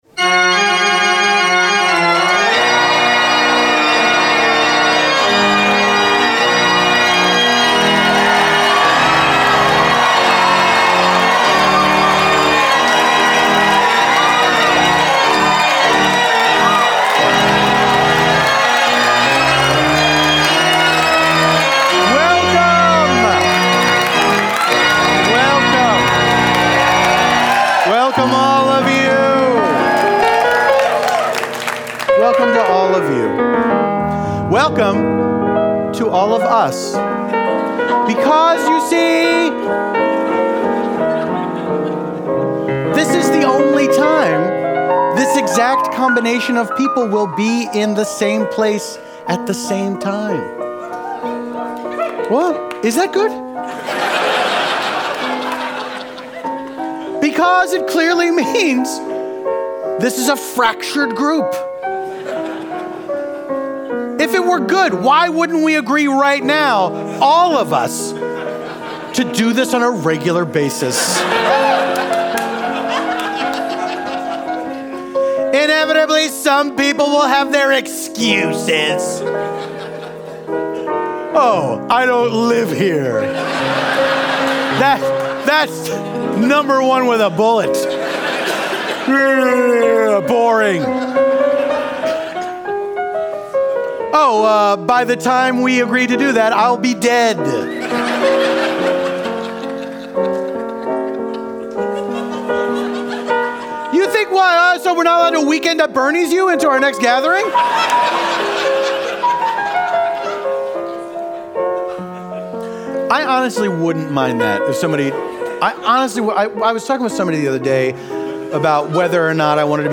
This time out, Paul's special guest is comedian Matt Rogers of Las Culturistas! Then, they are joined by improvisers Eugene Cordero and Tawny Newsome, to improvise a story set in Orlando, Florida.
Orlando, Florida: Live from The Bell House (w/ Matt Rogers, Eugene Cordero, Tawny Newsome)